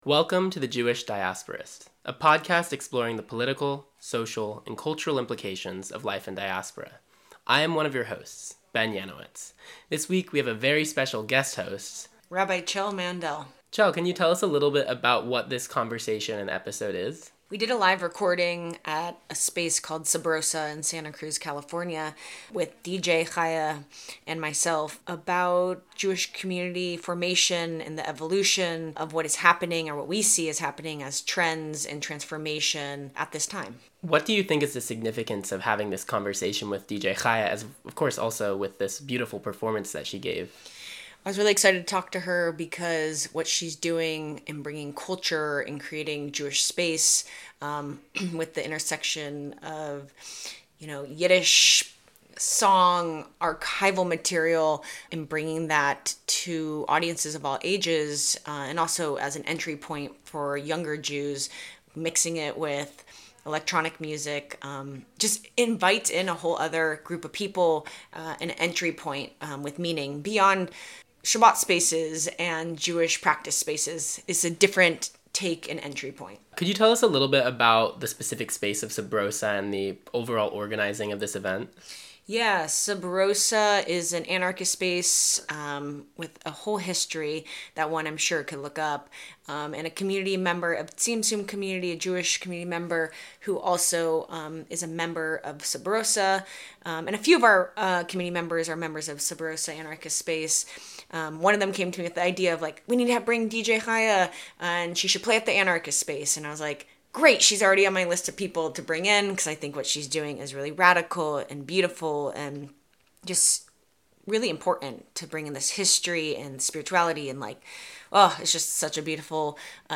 This episode was recorded live on September 15th.